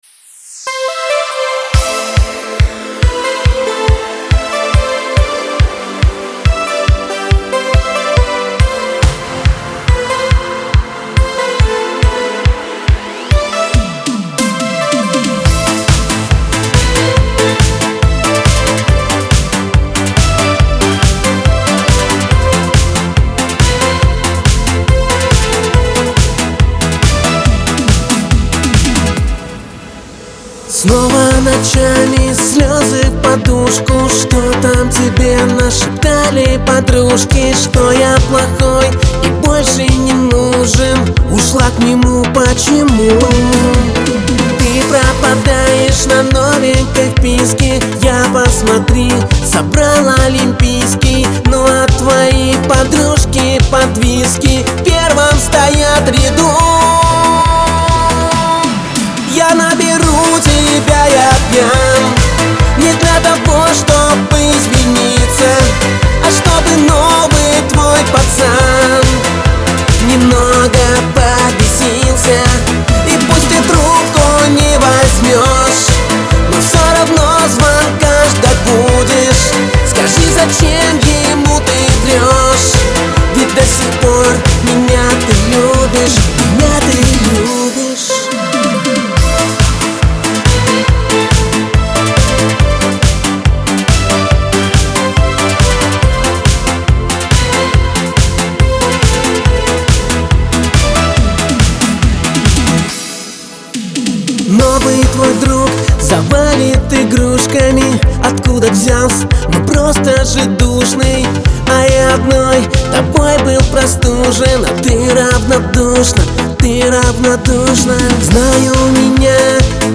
диско
грусть